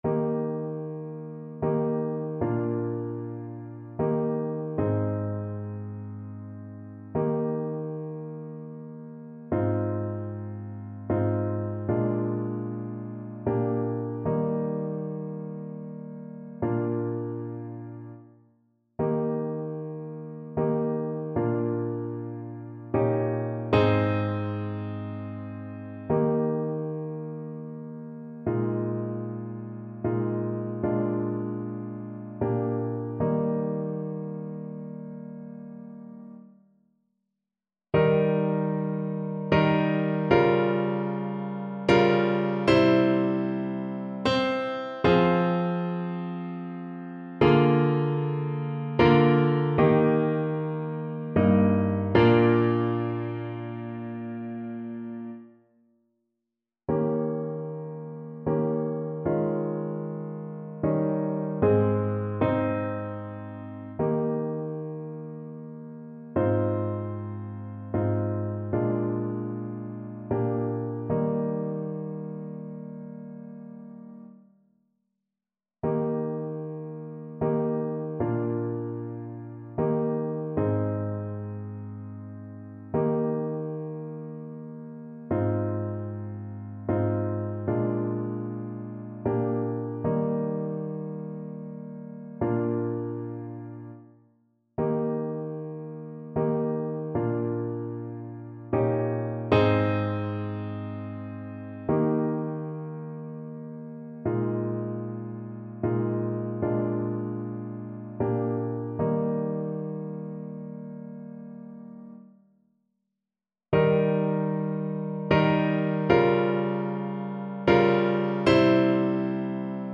Piano version
No parts available for this pieces as it is for solo piano.
3/4 (View more 3/4 Music)
Sehr langsam =76
Classical (View more Classical Piano Music)